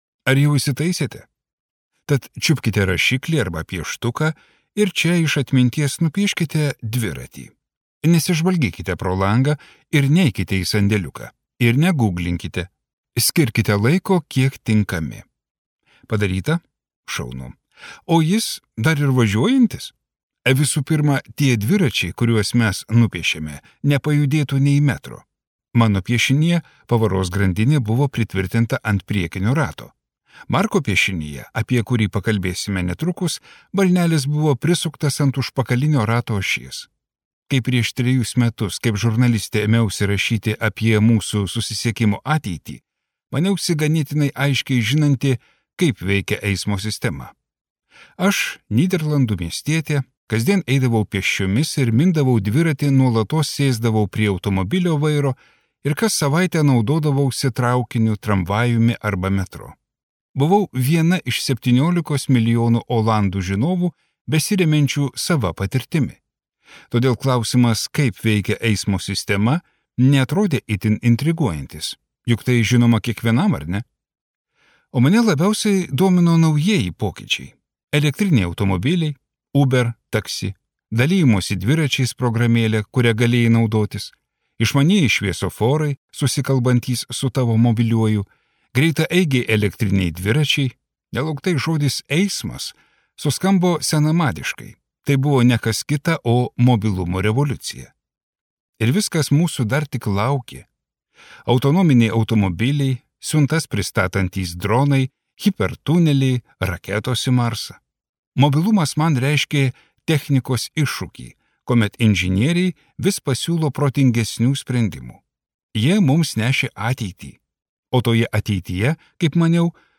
Skaityti ištrauką play 00:00 Share on Facebook Share on Twitter Share on Pinterest Audio Greičiausiojo teisė.